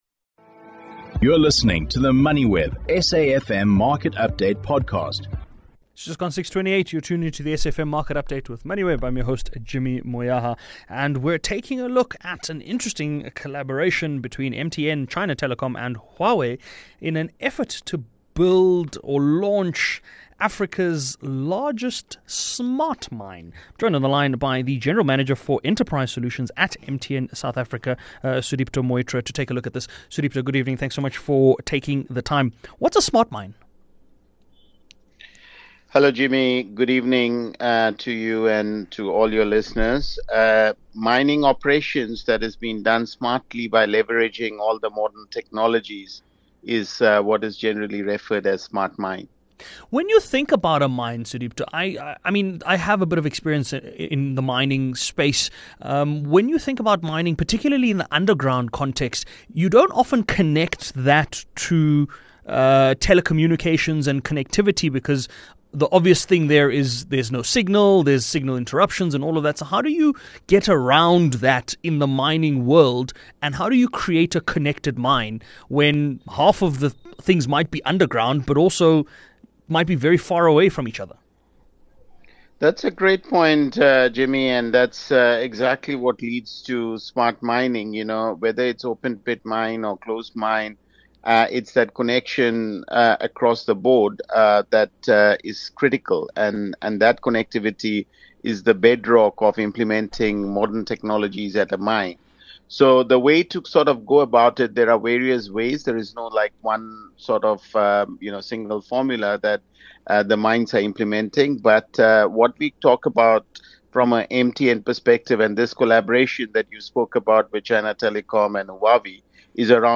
The programme is broadcasted Monday to Thursday nationwide on SAfm (104 – 107fm), between 18:00 and 19:00.